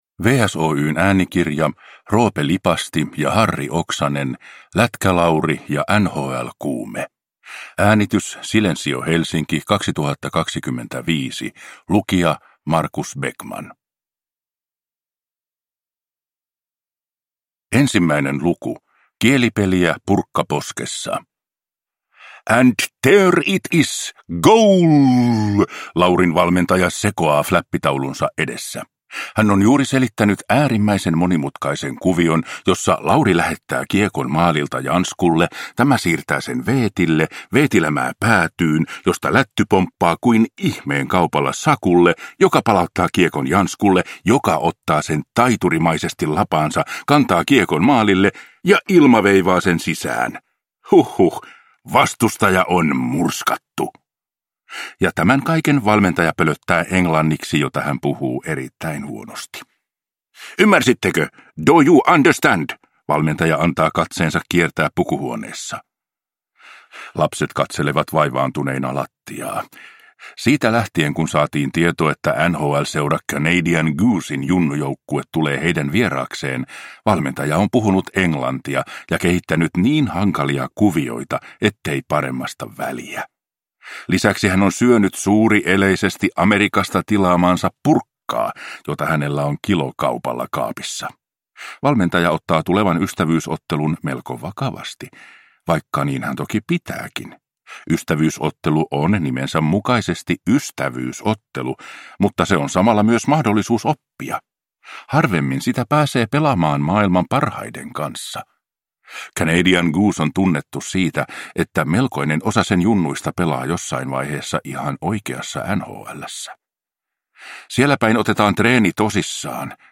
Lätkä-Lauri ja NHL-kuume – Ljudbok